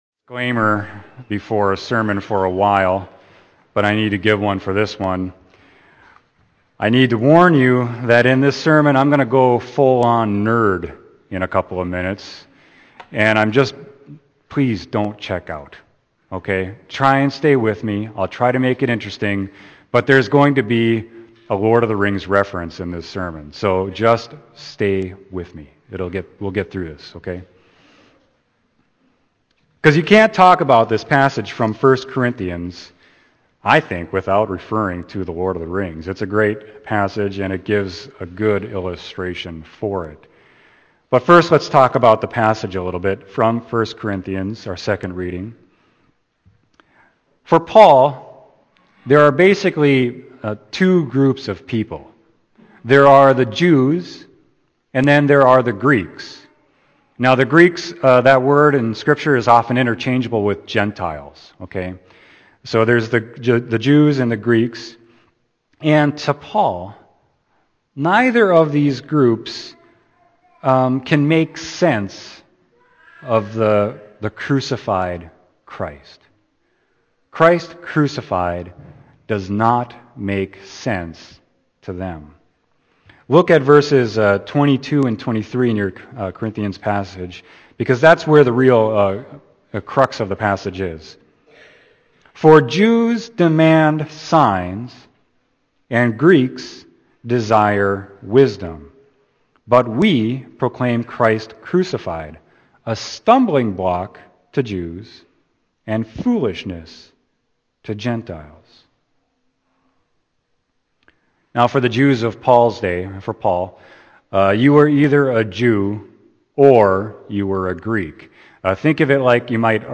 Sermon: 1 Corinthians 1.18-25